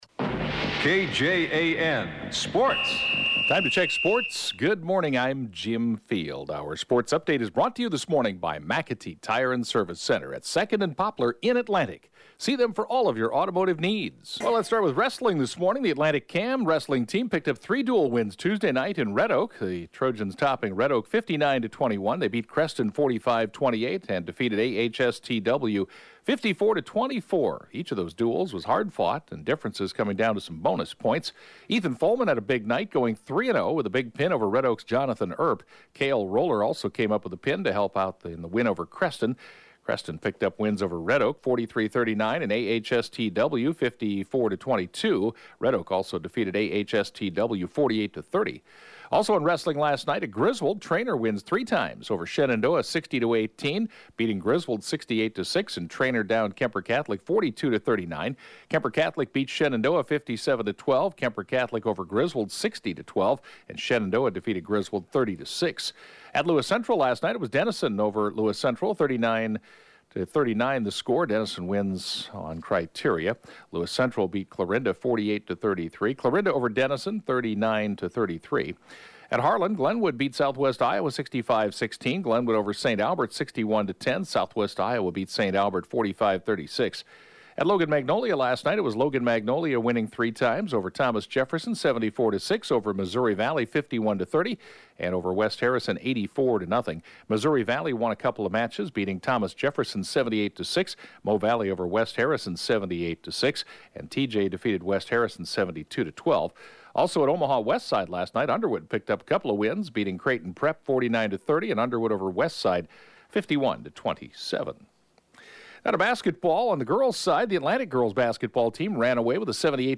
(Podcast) KJAN Morning News & Funeral report, 12/11/19